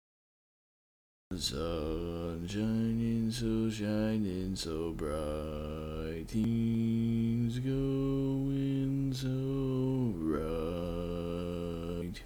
Key written in: F Major
Each recording below is single part only.